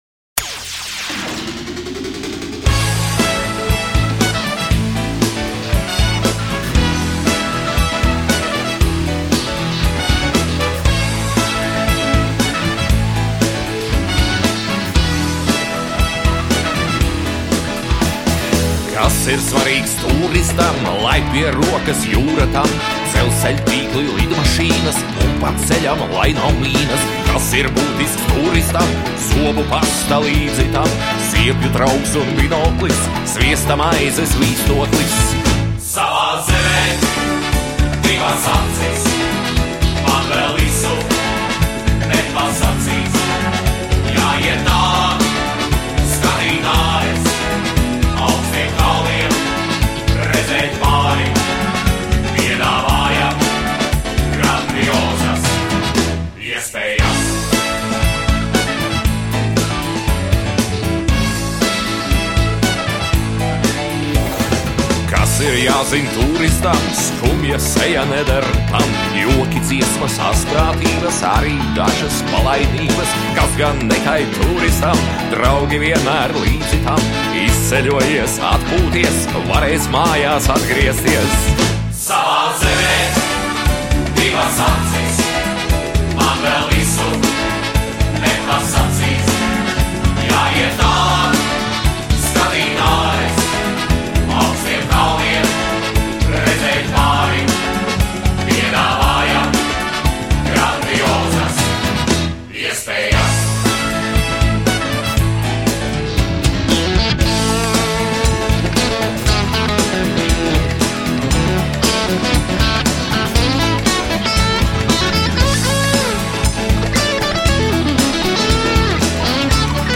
Himna ( mp3 3.0Mb)